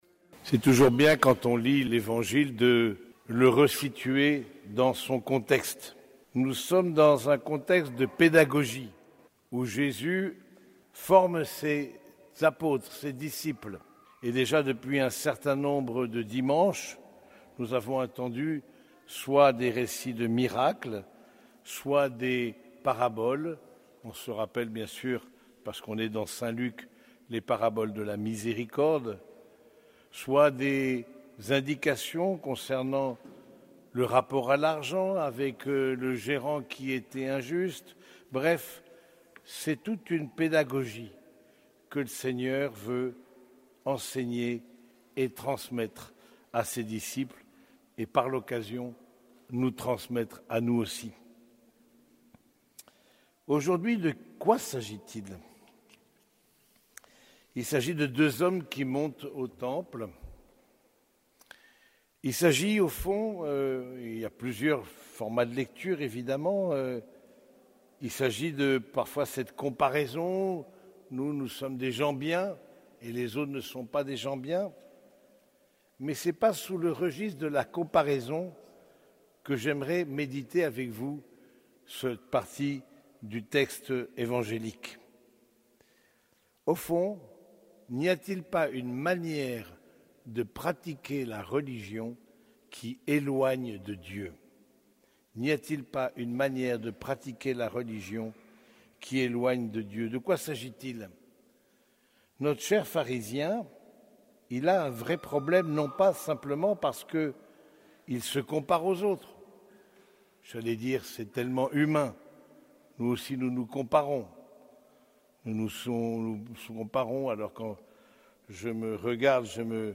Homélie du 30e dimanche du Temps Ordinaire